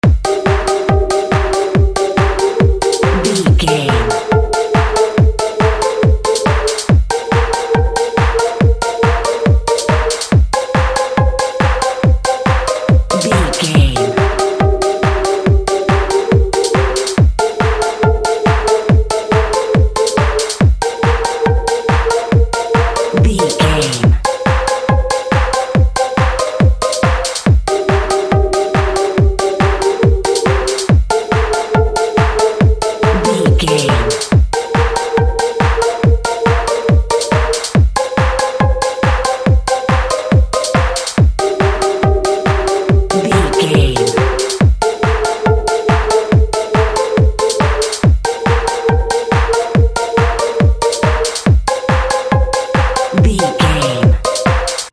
Ionian/Major
E♭
groovy
uplifting
bouncy
drum machine
synthesiser
synth lead
synth bass